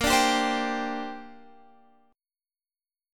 Bb6add9 chord